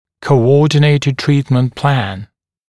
[kəu’ɔːdɪneɪtɪd ‘triːtmənt plæn] [коу’о:динэйтид ‘три:тмэнт плэн] скоординированный план лечения, согласованный план лечения (также co-ordinated treatment plan)